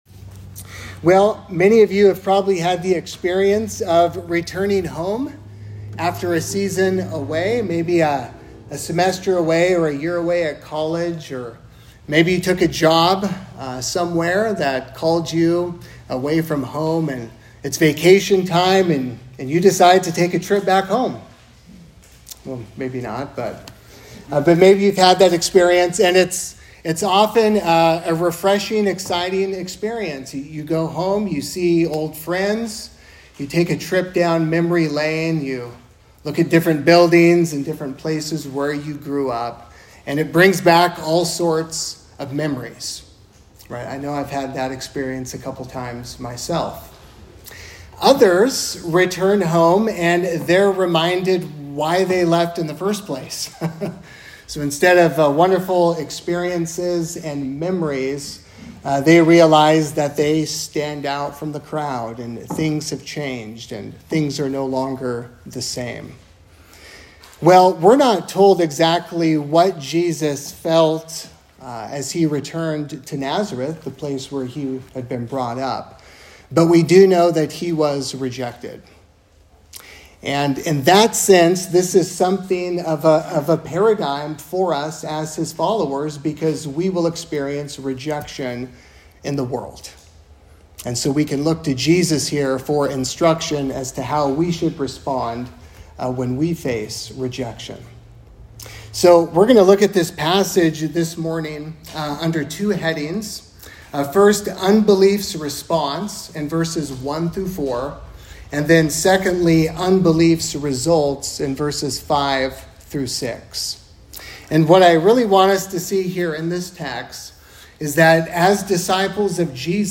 3Rivers Presbyterian Church - Sermons